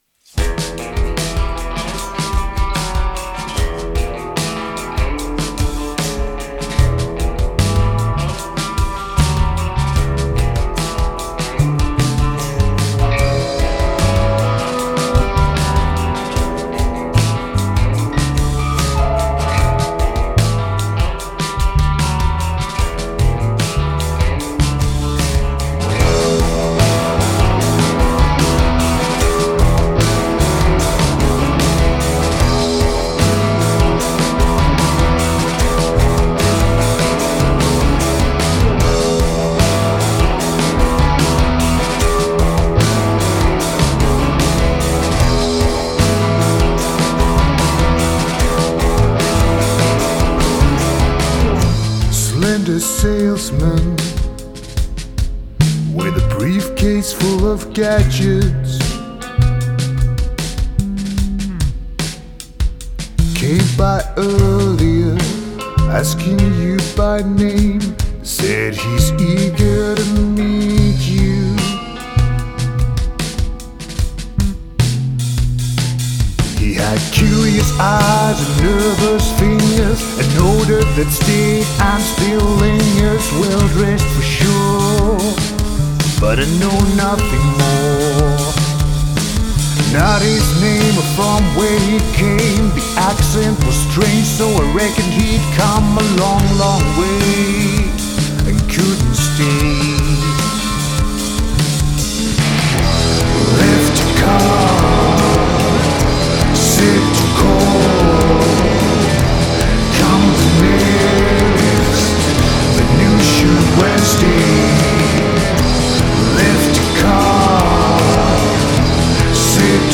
Lots of dissonance, distortion and saturation.
Very nice production and an enchanting sound landscape.